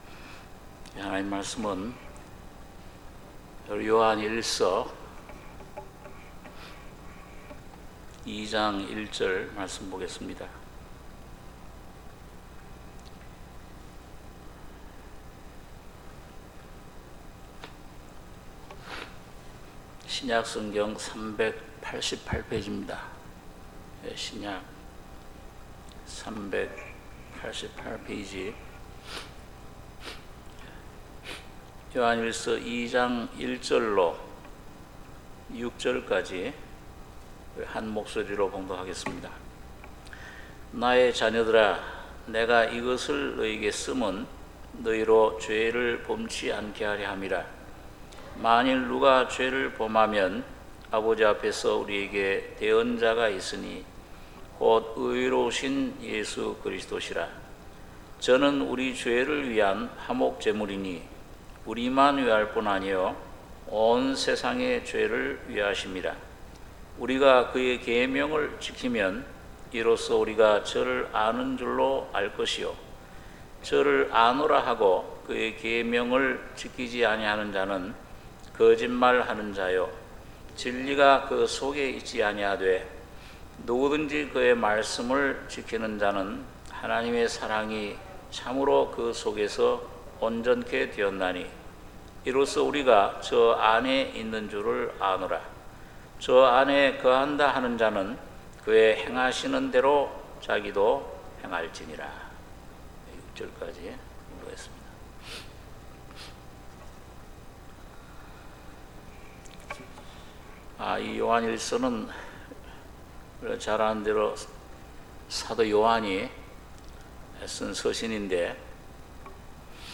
수요예배 요한일서 2:1-6